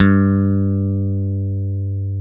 Index of /90_sSampleCDs/Roland L-CDX-01/BS _Rock Bass/BS _Dan-O Bass